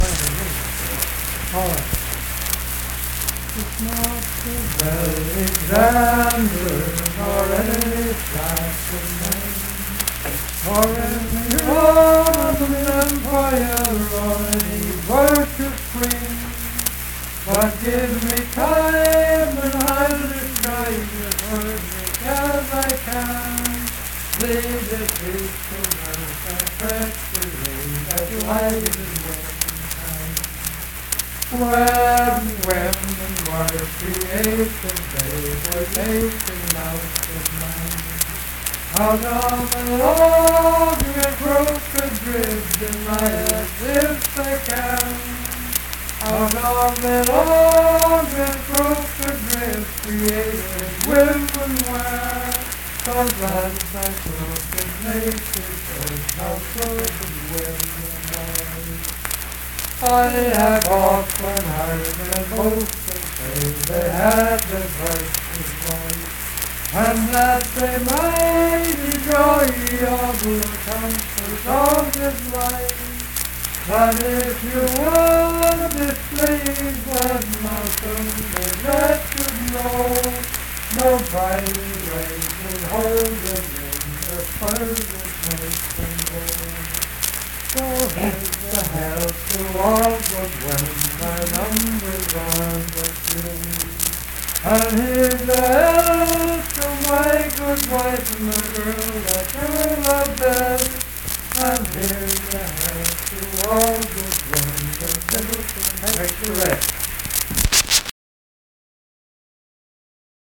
Unaccompanied vocal music
Richwood, Nicholas County, WV.
Voice (sung)